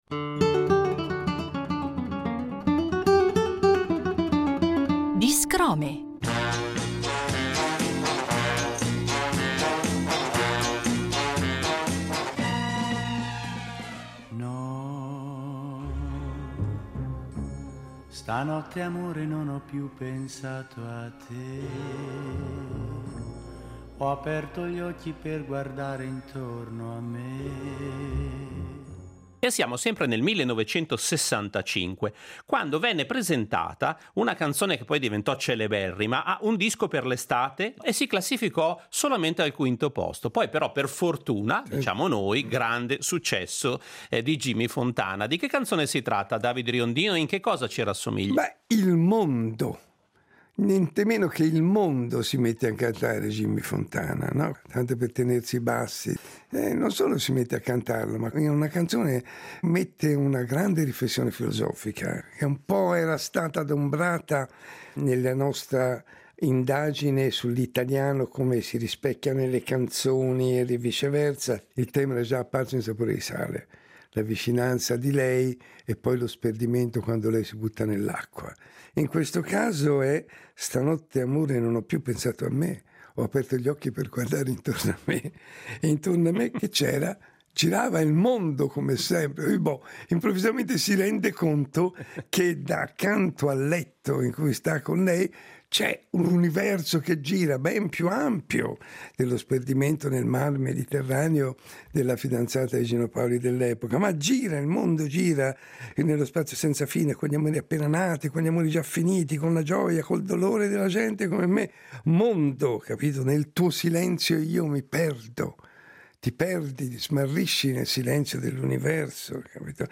Percorriamo questi anni a suon di giradischi con i mirabolanti racconti di David Riondino,